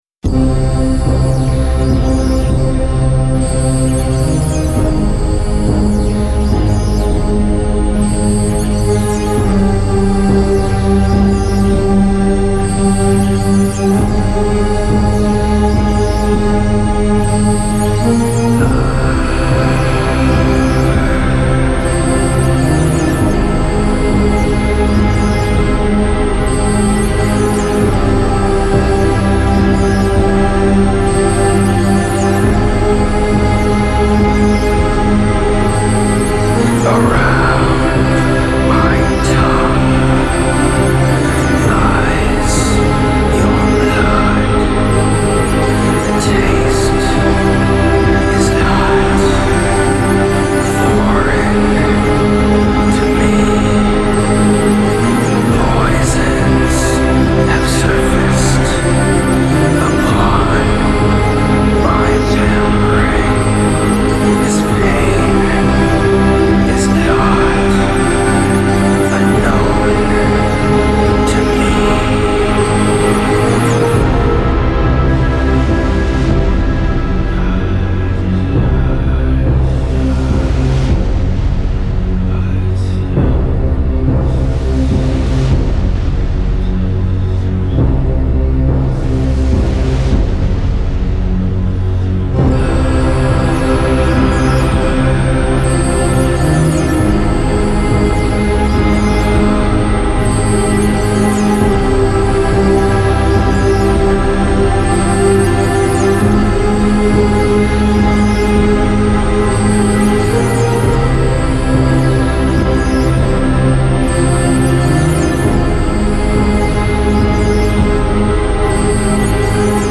Dark ambient noise
Prepare to dive into an ocean of darkness.